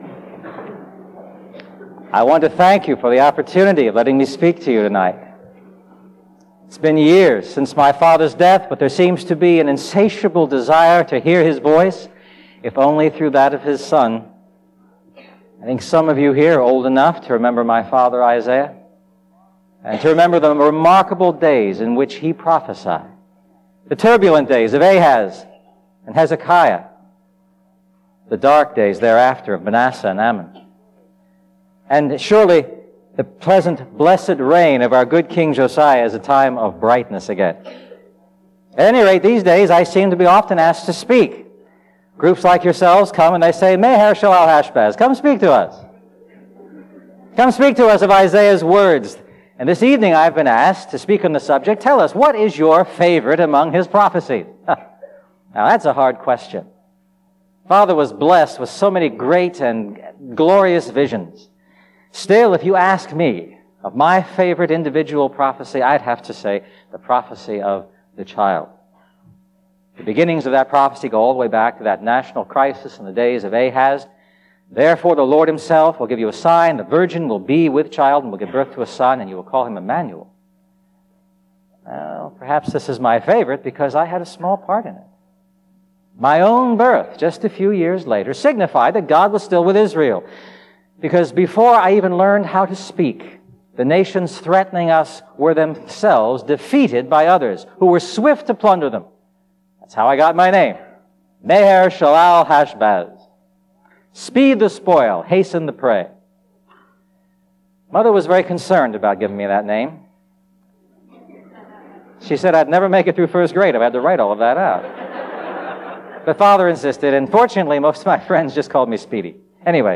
A message from the series "The Lord Saves."